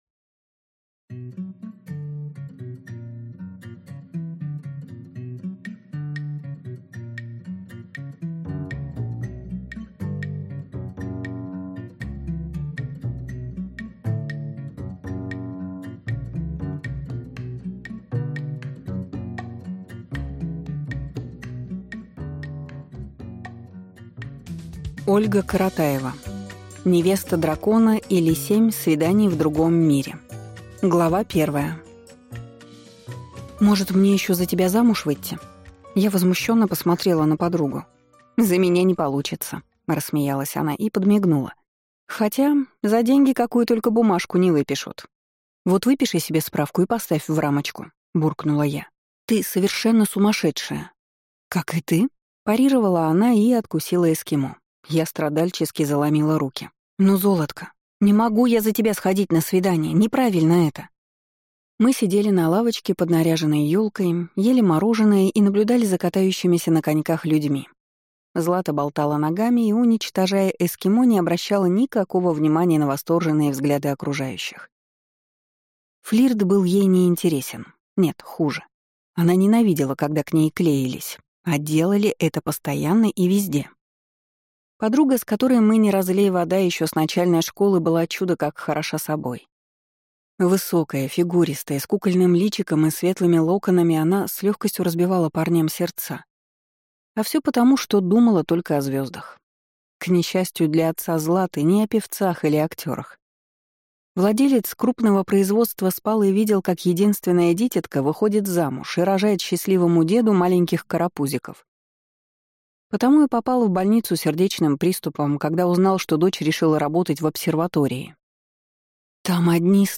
Аудиокнига Невеста дракона, или Семь свиданий в другом мире | Библиотека аудиокниг